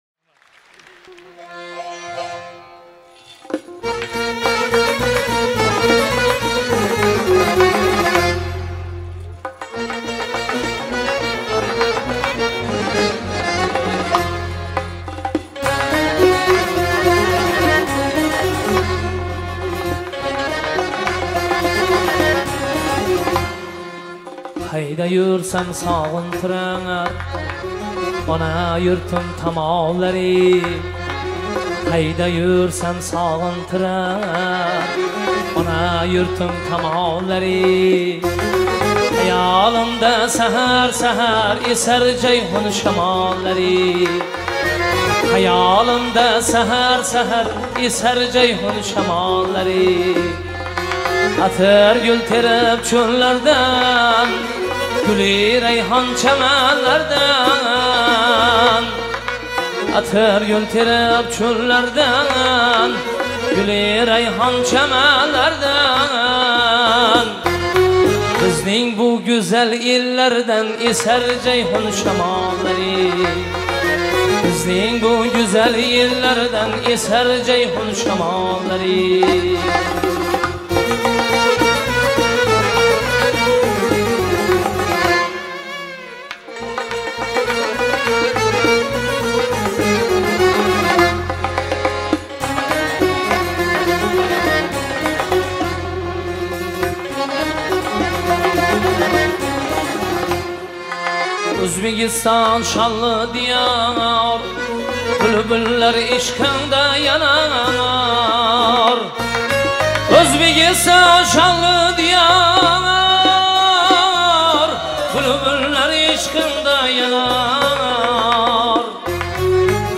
Трек размещён в разделе Узбекская музыка / Поп / 2022.